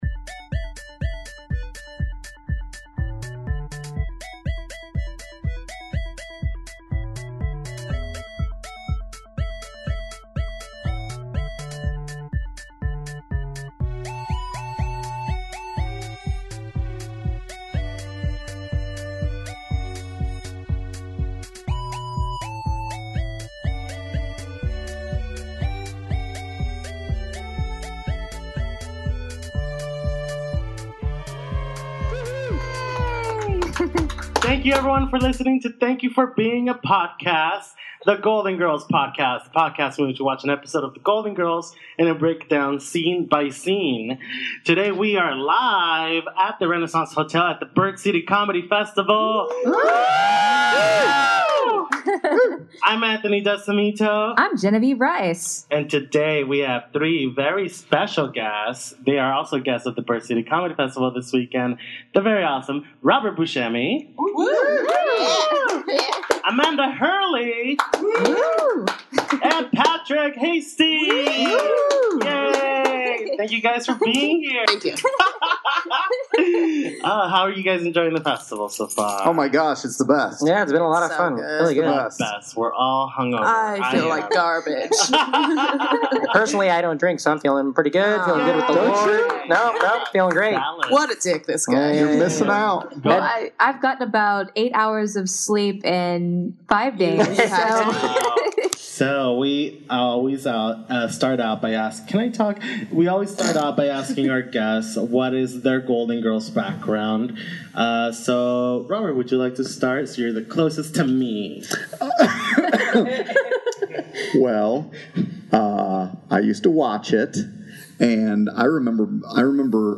on the LIVE Golden Girls podcast at the Bird City Comedy Festival in Phoenix!